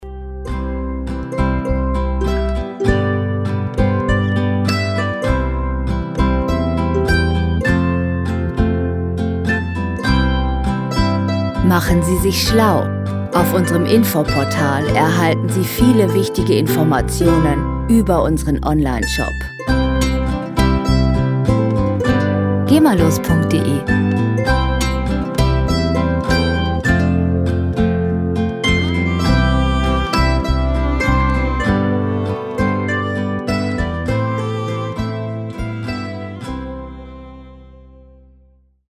Musikstil: Country
Tempo: 100 bpm
Tonart: C-Dur
Charakter: wehmütig, erfüllt